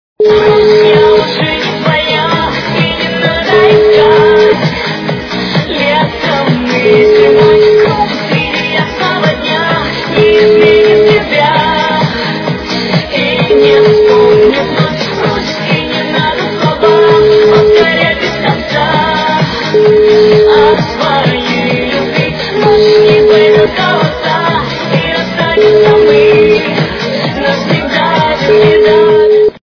качество понижено и присутствуют гудки.